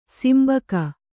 ♪ simbaka